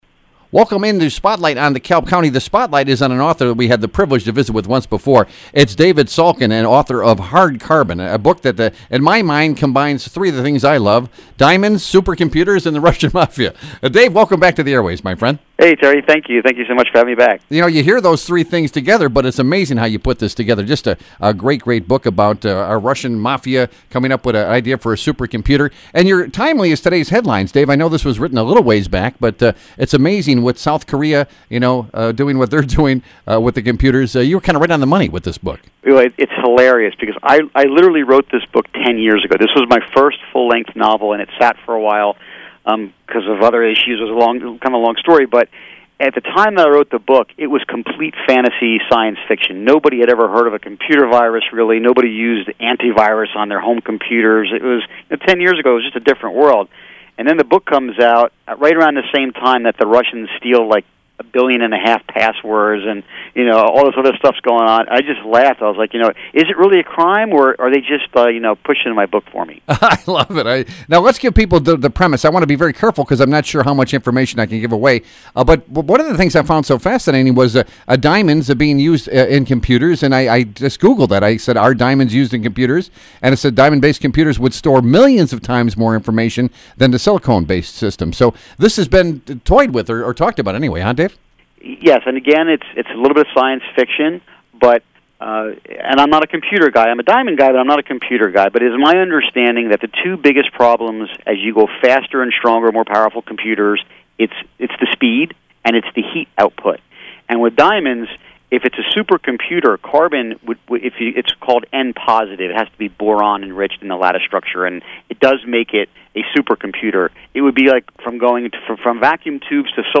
interviewed on WLBK